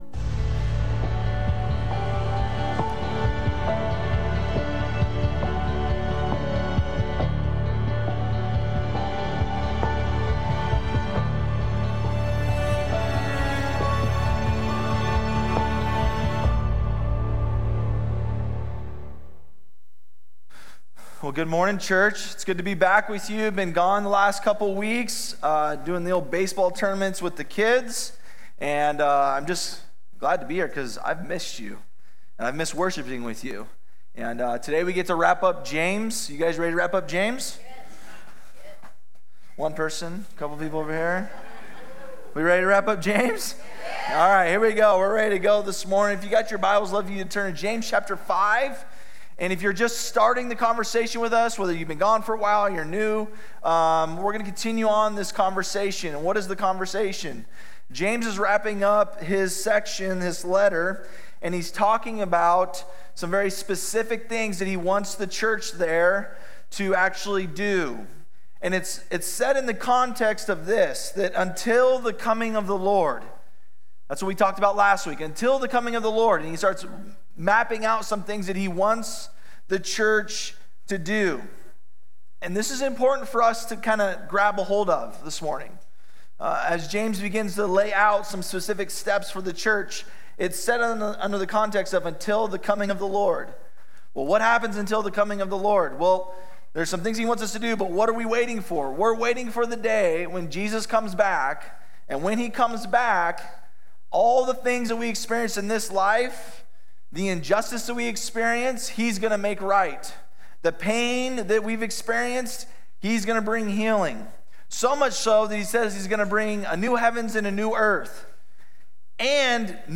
The Book Of James | Prayer Real Life Ministries Treasure Valley Sermon podcast